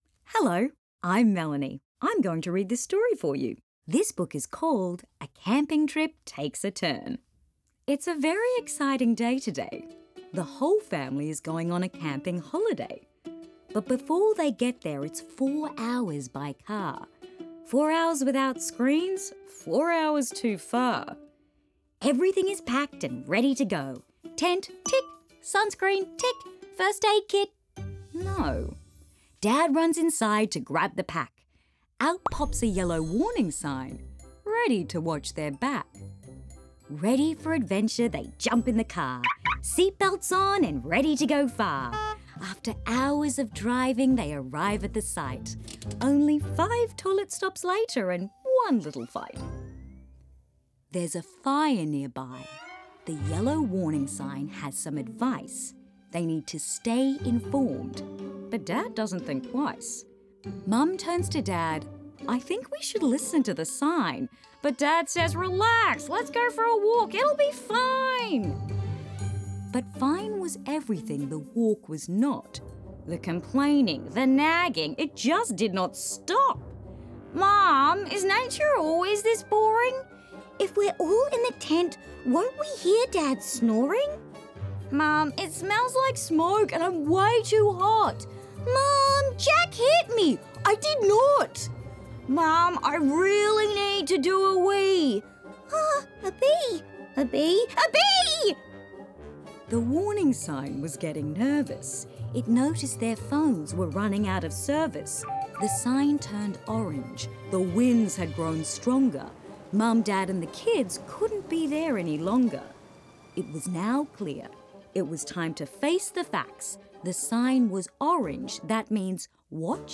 Each story featured on this landing page is available with PDF with an accompanying audiobook that can be used together, or separately.
AWS-Camping_AudioBook.wav